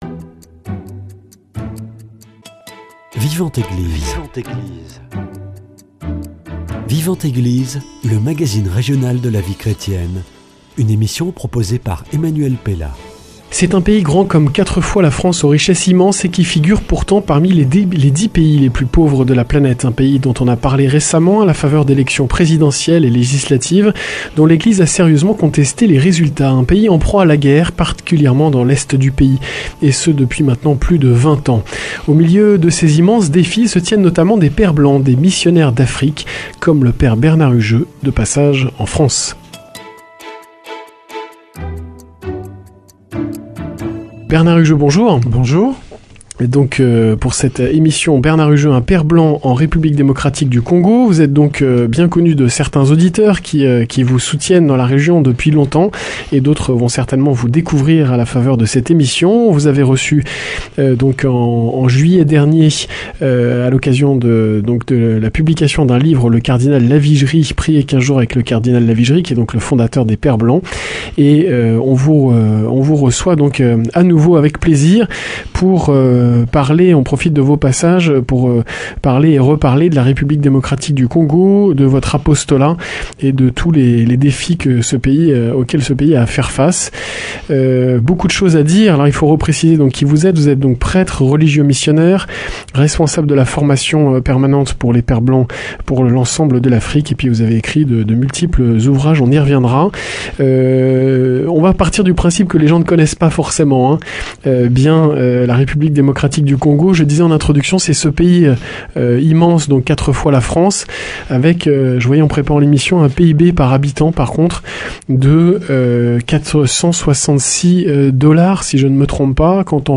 Rencontre à l’occasion d’un de ses passages en France.